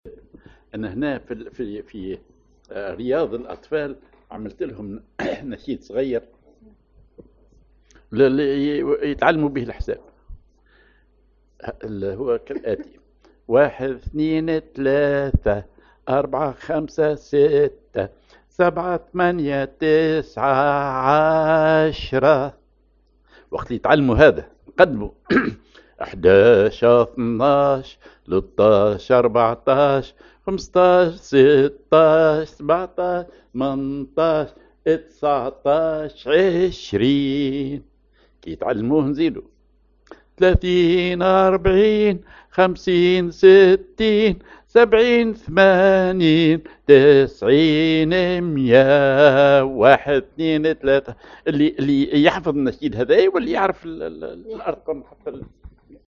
Maqam ar صول كبير
genre نشيد